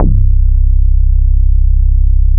MIRROR BASS.wav